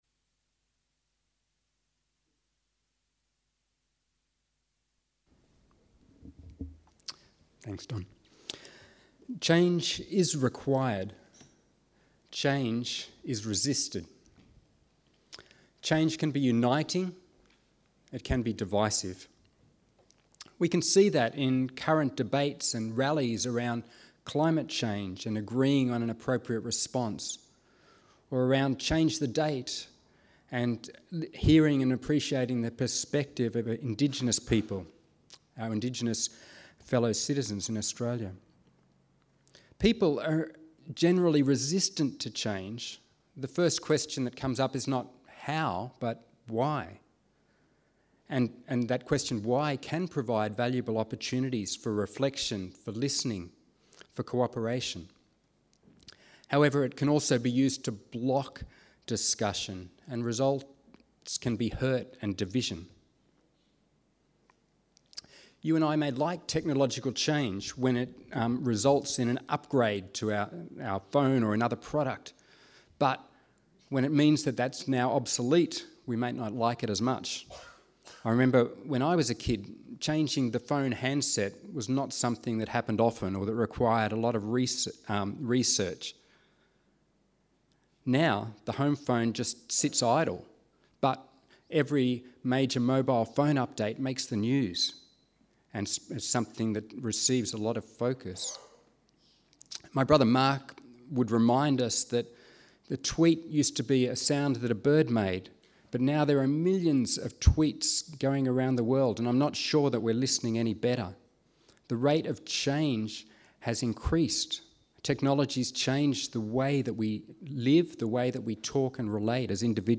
Sermons 2020